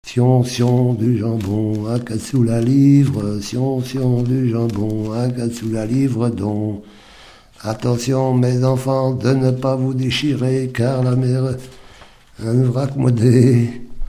Enfantines - rondes et jeux
Pièce musicale inédite